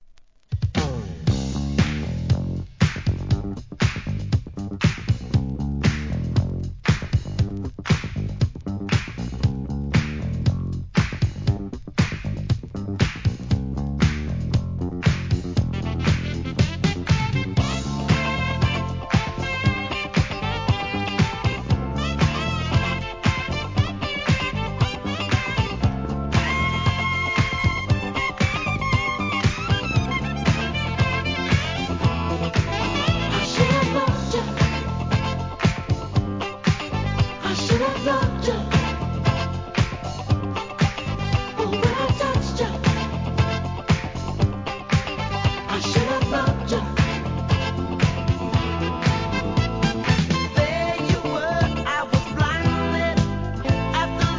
SOUL/FUNK/etc... 店舗 ただいま品切れ中です お気に入りに追加 1979年のUK DISCOヒット!!